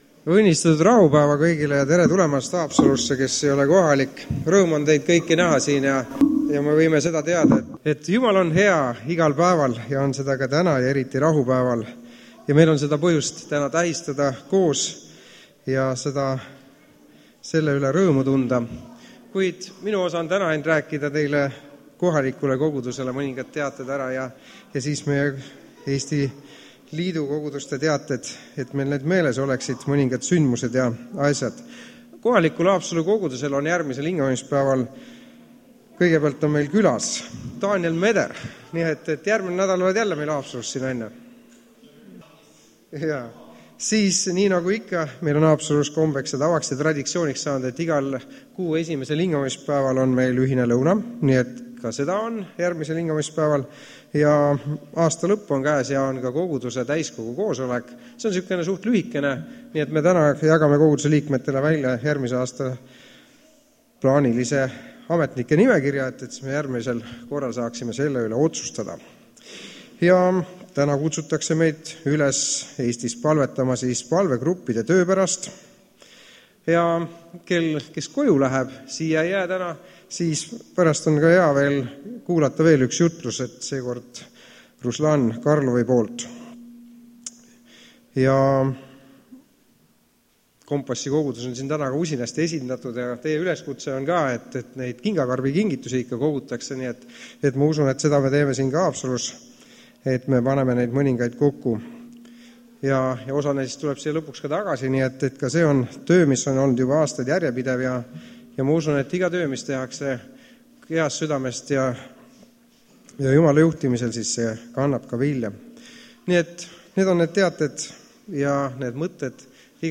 Haapsalus on täna koos noored üle terve Eesti. Eriti mitmed on Tallinna Kompassi kogudusest ja nemad laulavad ka ülistust.
Koosolekute helisalvestused